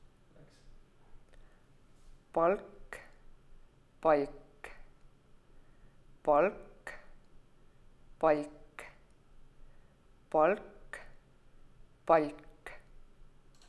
Joonisel 11.4 on vasakul sõna palk palataliseerimata /l/-ga ning paremal palataliseeritud /lʲ/-ga (näide on failist
Palataliseeritud variandi puhul on näha, kuidas vokaali lõpuosas esimene formant pisut langeb ja teine formant tugevasti tõuseb.
Palataliseerimata palk (vasakul) ja palataliseeritud pal’k (paremal).